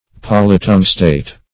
Polytungstate \Pol`y*tung"state\, n. A salt of polytungstic acid.